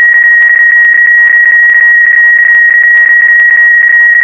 QPSK 31 : Phase Shift Keying 31 Bauds
QPSK31.wav